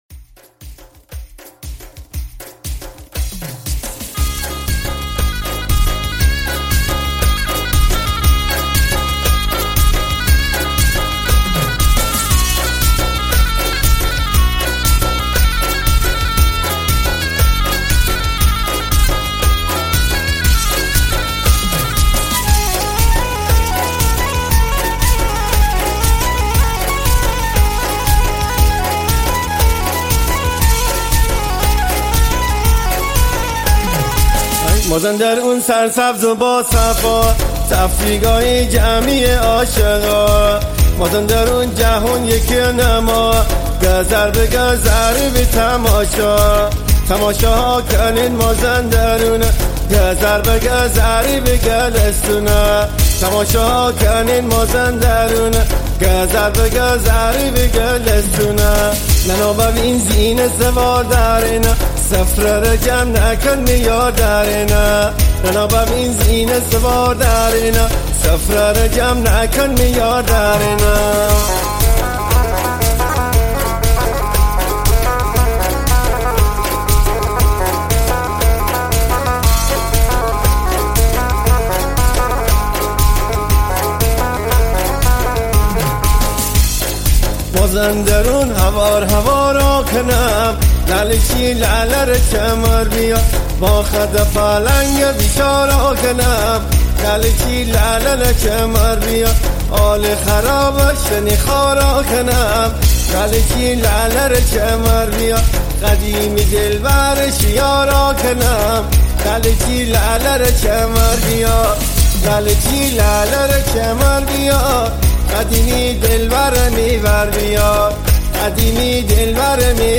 دانلود آهنگ های مازندرانی جدید